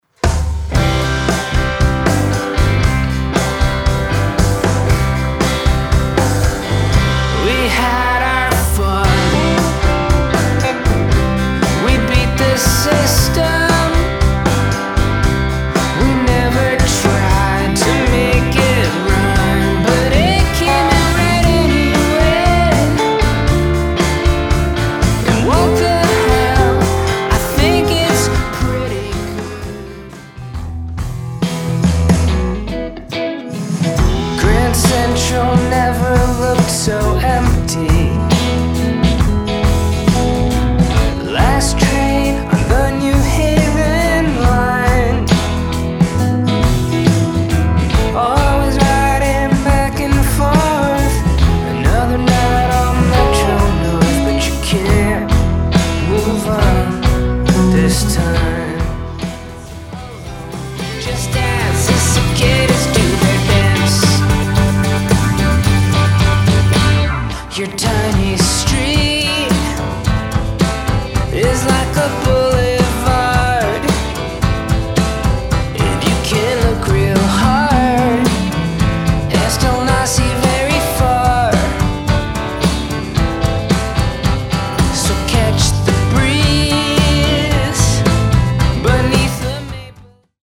Drums and percussion